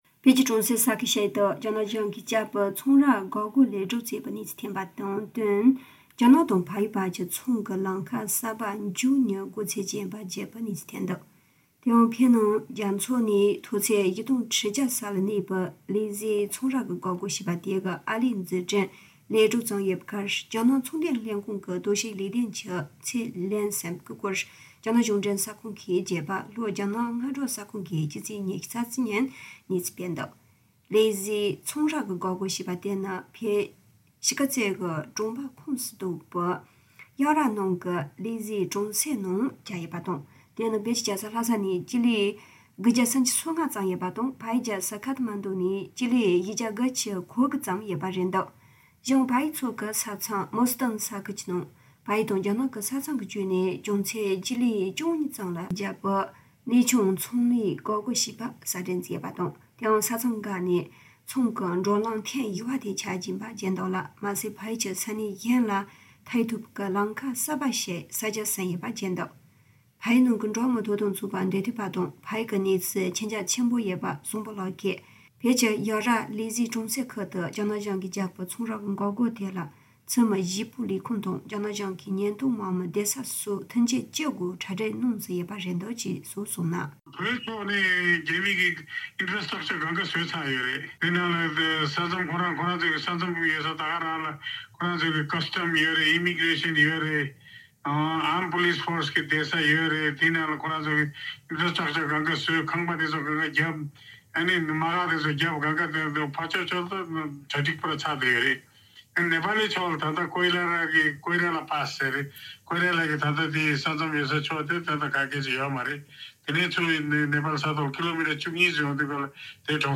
སྒྲ་ལྡན་གསར་འགྱུར། སྒྲ་ཕབ་ལེན།
དམིགས་བསལ་གསར་འགྱུར་ནང་།